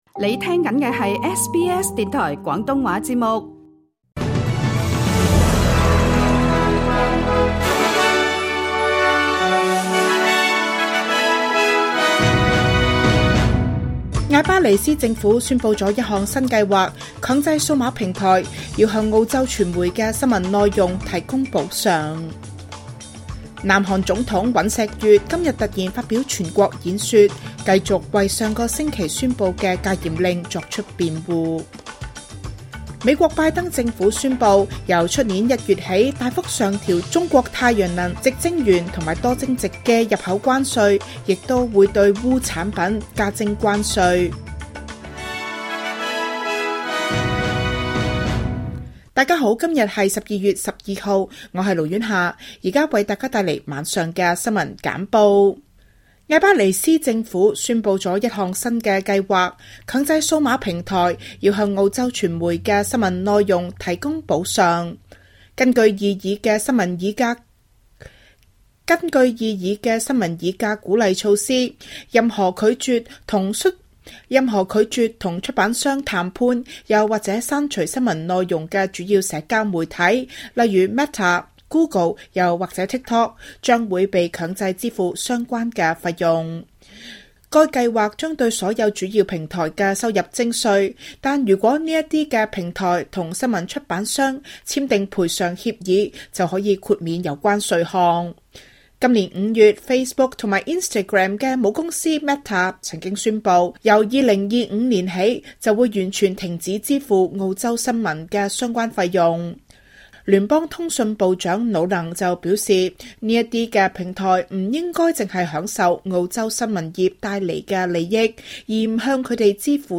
SBS 晚間新聞（2024年12月12日） Play 07:31 SBS 廣東話晚間新聞 SBS廣東話節目 View Podcast Series 下載 SBS Audio 應用程式 其他收聽方法 Apple Podcasts  YouTube  Spotify  Download (6.88MB)  請收聽本台為大家準備的每日重點新聞簡報。